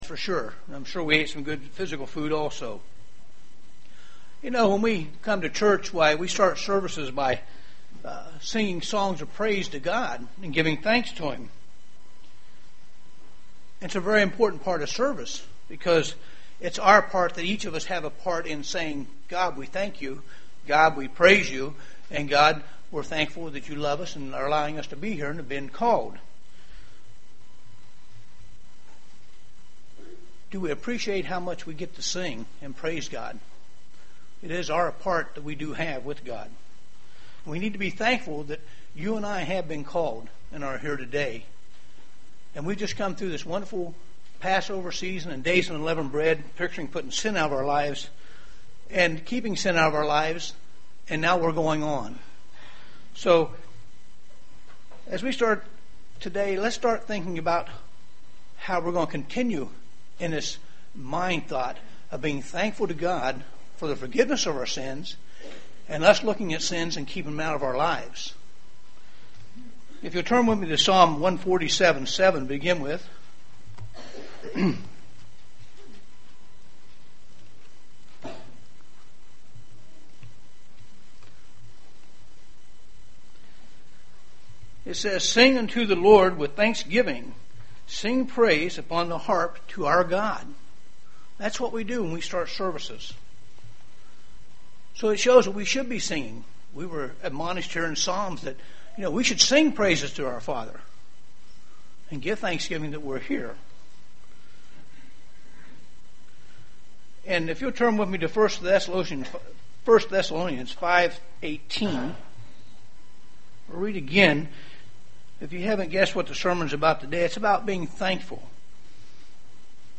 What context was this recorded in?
Given in Dayton, OH